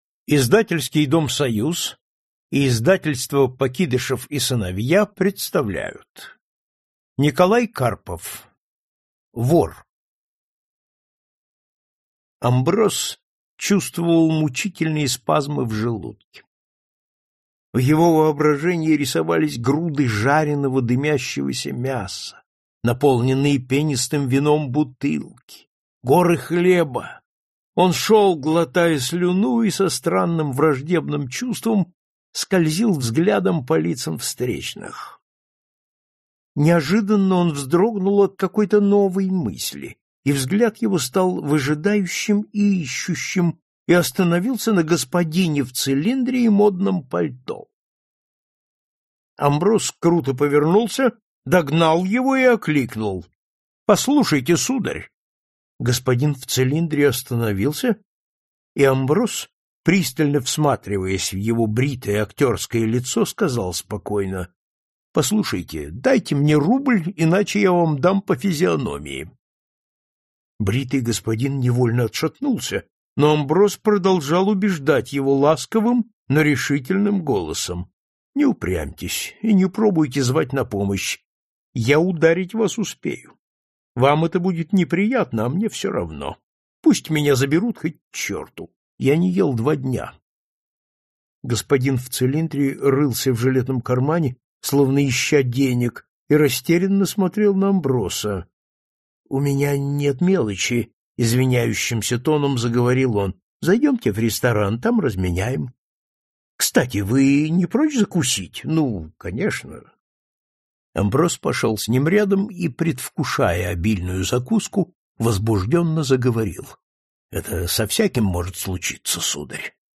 Аудиокнига Вор и другие повести | Библиотека аудиокниг